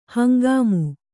♪ hangāmu